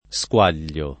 [ S k U# l’l’o ]